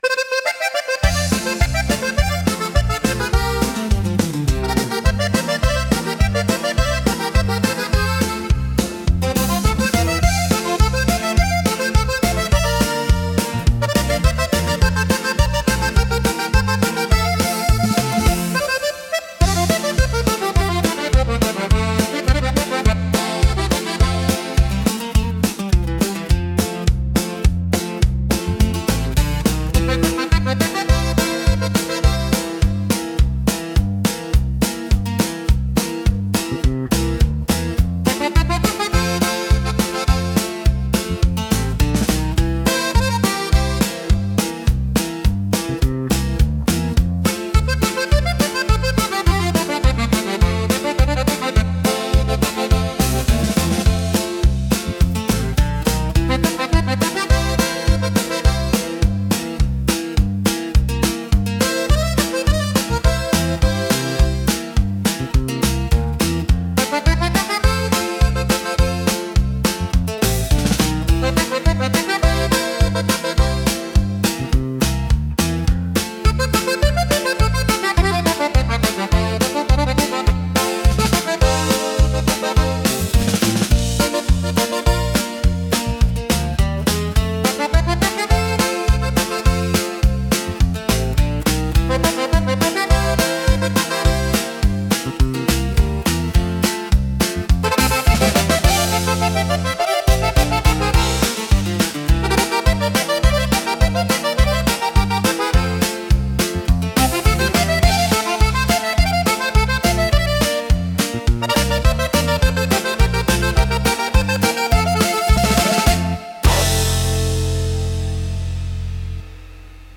感情の深みと躍動感を強く伝え、力強く印象的な空間づくりに貢献します。